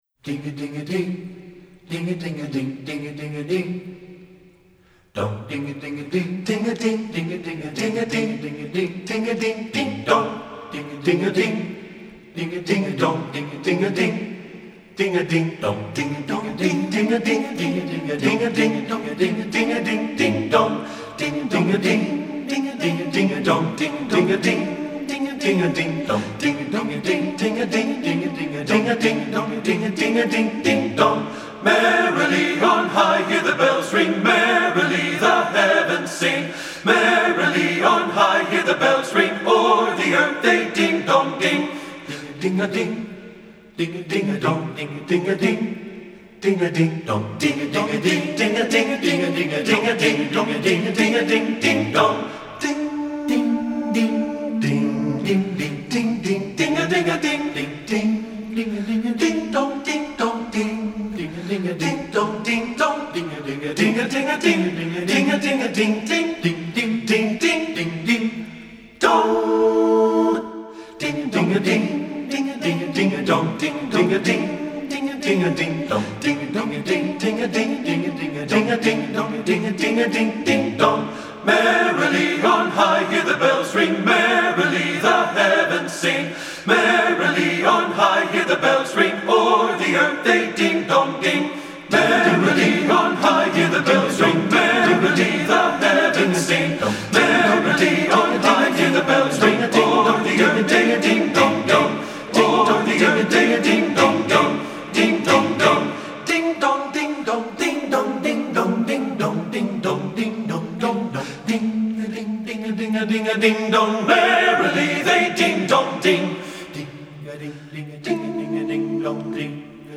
Voicing: TB and Piano